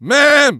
engineer_medic03.mp3